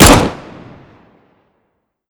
Index of /server/sound/weapons/dod_m1911
usp_unsilenced_01.wav